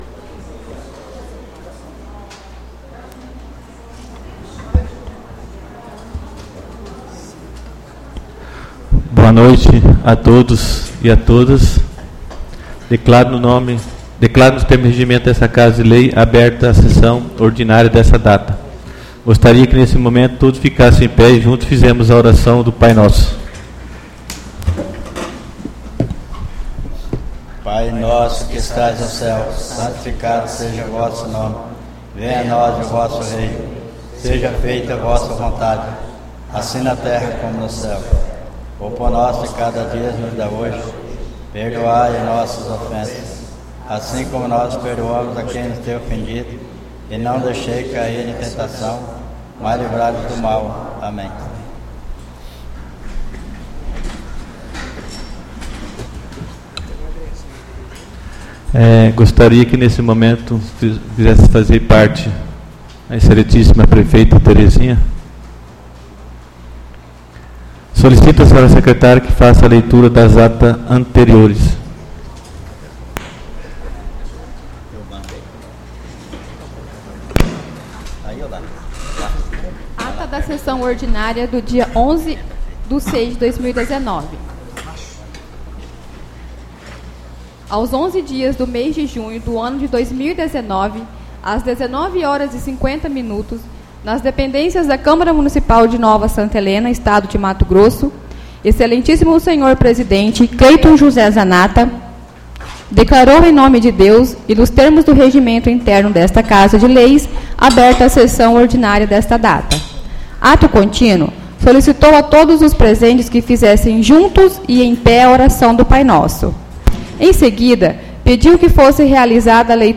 Áudio da Sessão Ordinária 18/06/2019